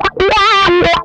MANIC WAH 17.wav